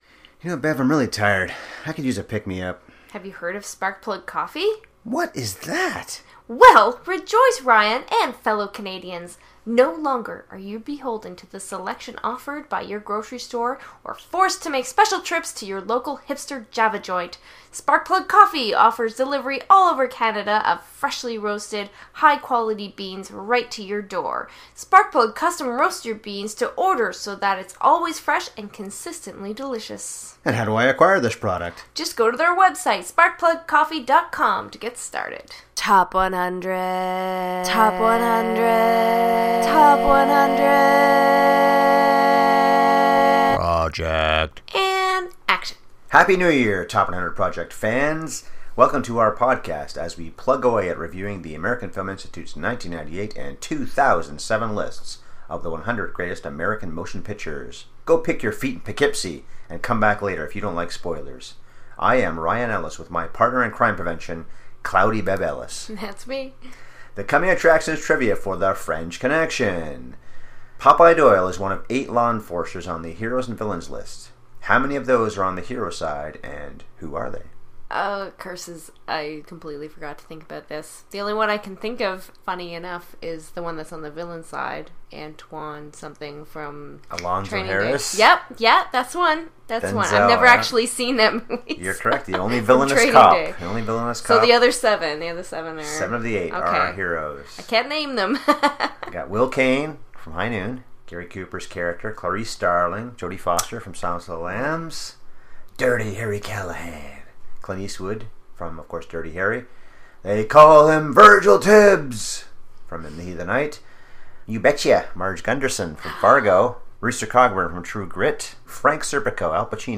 The French Connection (46:45) This episode is rated R for some naughty words.